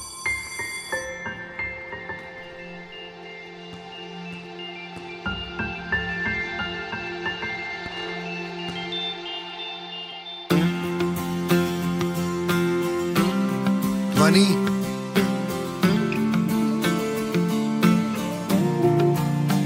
Featured in Bollywood Ringtones